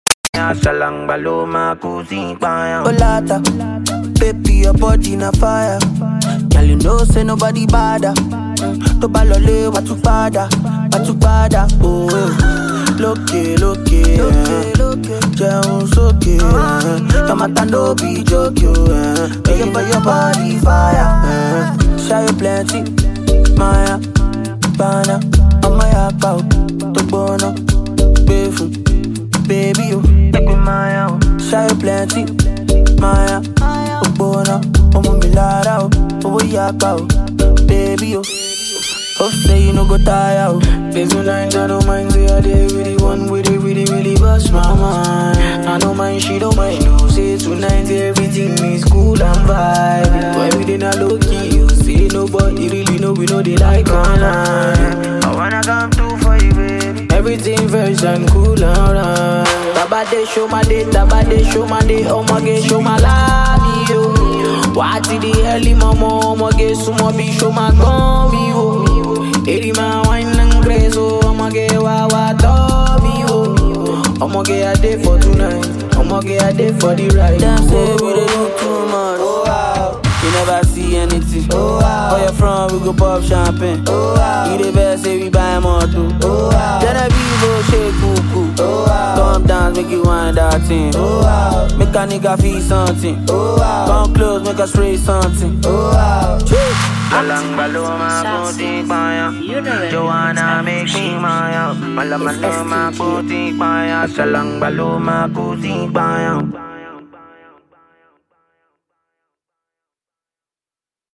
groovy tune